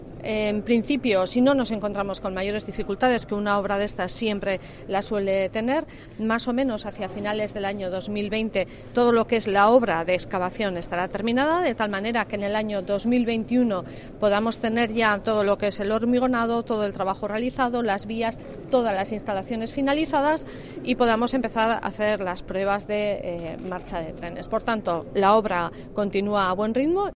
Arantxa Tapia, consejera de Infraestructuras.